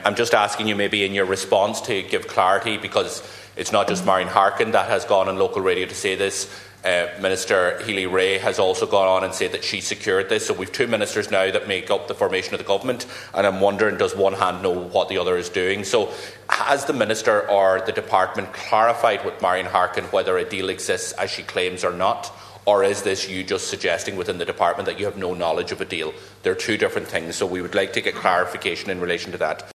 Sinn Fein Deputies Pearse Doherty and Padraig MacLochlainn questioned the claims made on Highland Radio in the chamber this morning.
Deputy Doherty said there needs to be clarity: